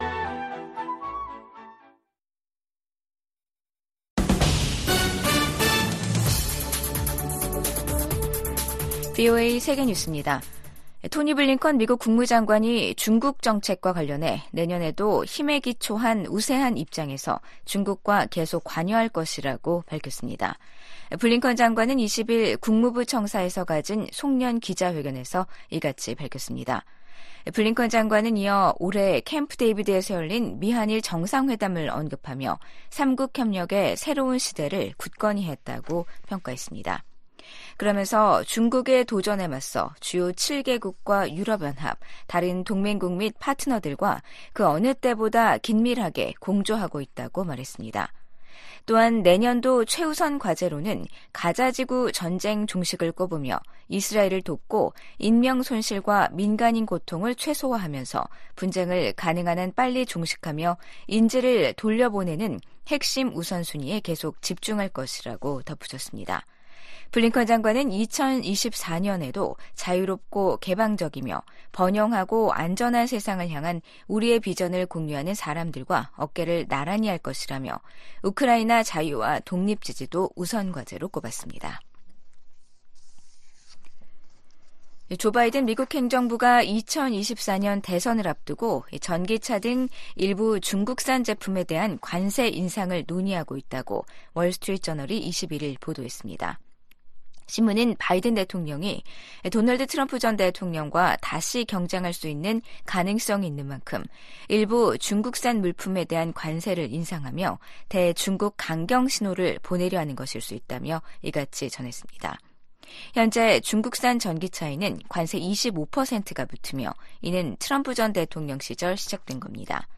VOA 한국어 간판 뉴스 프로그램 '뉴스 투데이', 2023년 12월 21일 2부 방송입니다. 유엔 총회가 북한의 조직적이고 광범위한 인권 침해를 규탄하는 결의안을 19년 연속 채택했습니다. 김정은 북한 국무위원장은 어디 있는 적이든 핵 도발에는 핵으로 맞서겠다고 위협했습니다. 토니 블링컨 미 국무장관이 내년에도 중국에 대한 견제와 관여 전략을 병행하겠다는 계획을 밝혔습니다.